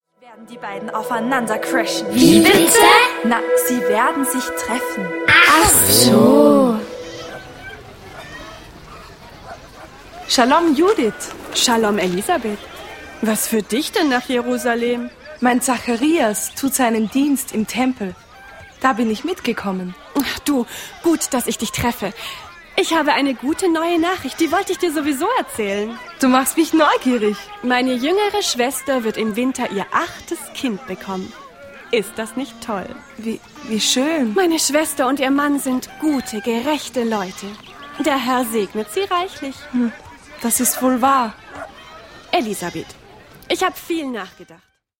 Weihnachtsmusical
Viele Lieder in kunterbunter Mischung prägen das Musical.